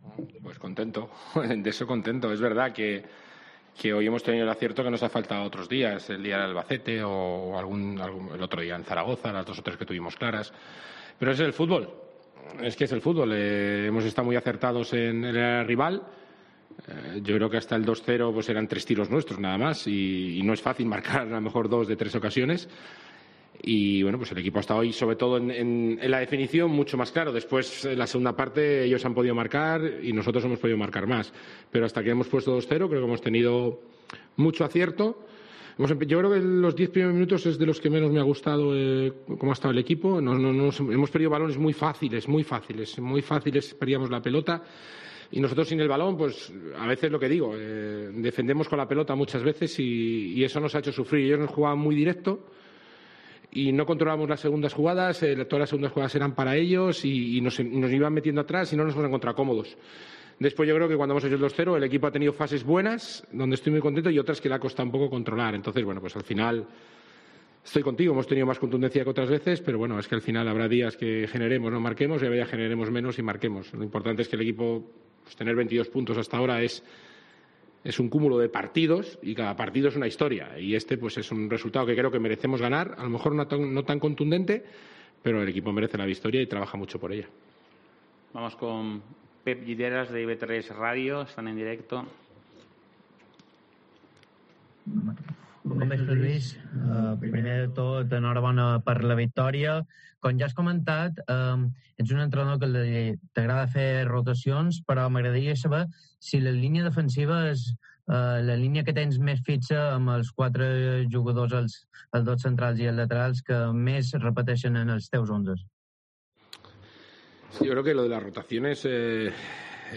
AUDIO: Escucha aquí las palabras del entrenador del Mallorca tras la victoria 3-0 ante la Deportiva Ponferradina